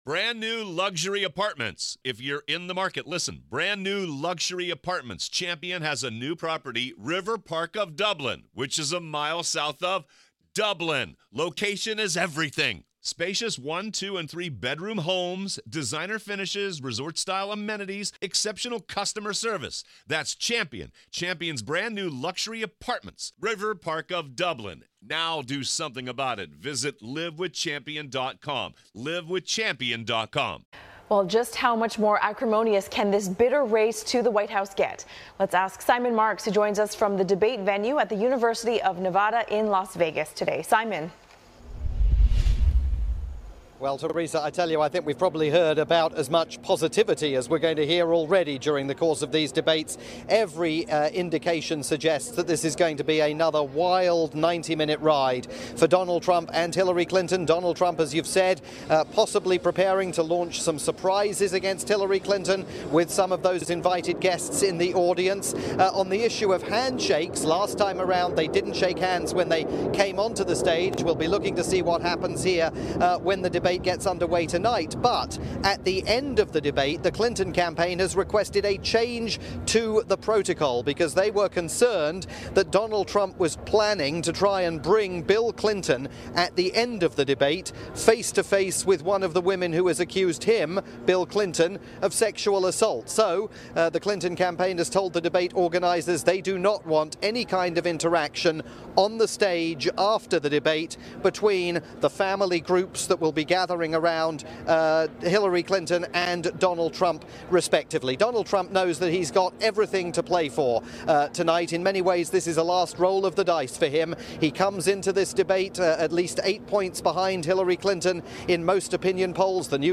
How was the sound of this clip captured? preview of the final TV debate between Trump and Clinton from "First Look Asia" on pan-Asian TV network Channel NewsAsia.